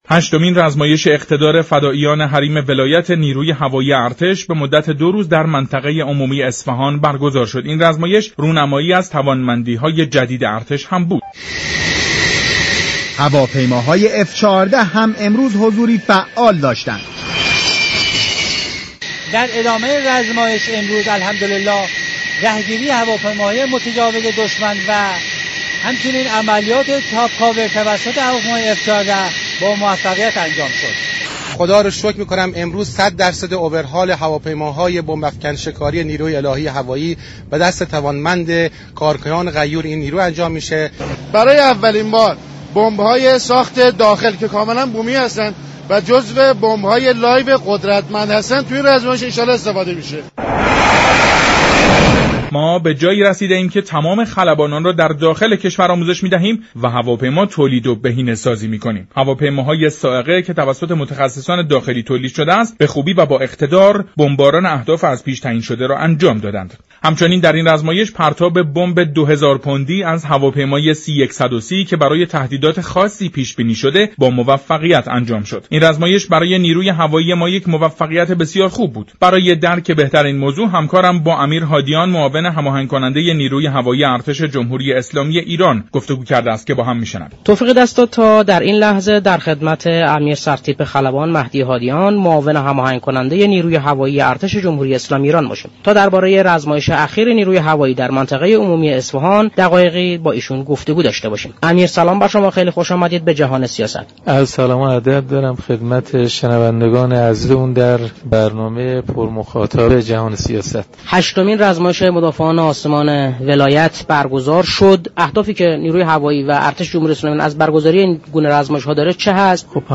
به گزارش شبكه رادیویی ایران، امیر سرتیپ خلبان مهدی هادیان معاون هماهنگ كننده نیروی هوایی ارتش جمهوری اسلامی ایران در گفتگو با برنامه جهان سیاست رادیو ایران با اعلام این خبر، افزود: در این نمایشگاه كه در دهه مبارك فجر برگزار خواهد شد، دستاوردهای چهل ساله نیروی هوایی ارتش در منطقه هوایی مهرآباد برای بازدید عموم به نمایش در خواهد آمد.